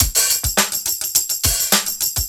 On top of the drums that I pulled from some of the tracks posted in the original thread, I also used this little drum break that I made with MODO Drum 2:
I was going for the break from “I Believe In Music” by the Kay Gees.